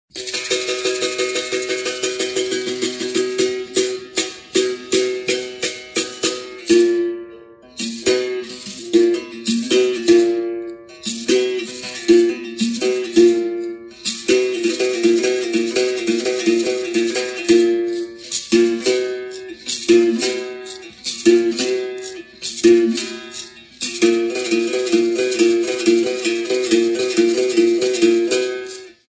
Instrumento de corda cujo som é obtido pela percussão de uma moeda ou arruela de metal, que o tocador segura na mão esquerda, num arame amarrado em um arco de madeira. Em uma das pontas é fixada uma cabaça que funciona com caixa de ressonância. Na mão direita o tocador segura uma varinha, também para percutir o arame, e o caxixi.
Berimbau
berimbau.mp3